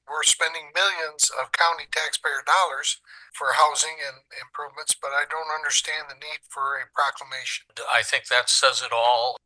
Republican commissioners John Gisler and Jeff Heppler both questioned the need for a resolution.